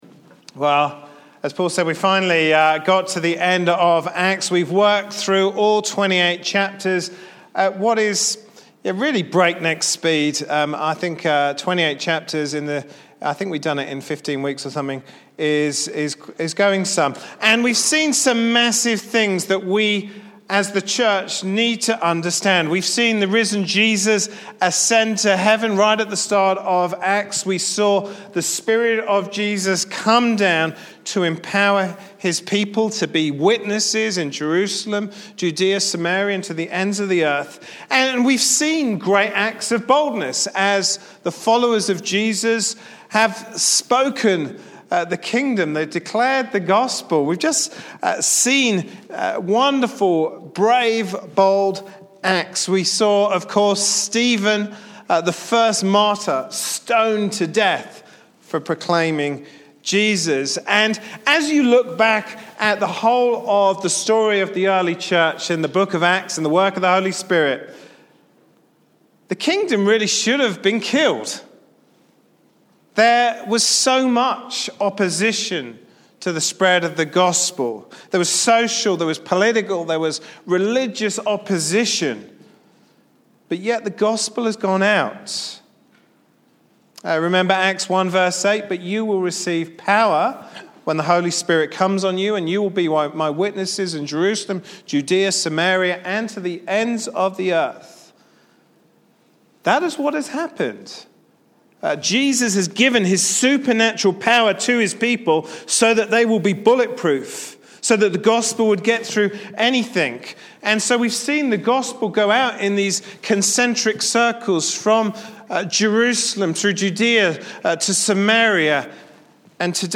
Passage: Acts 27-28 Service Type: Sunday morning service Topics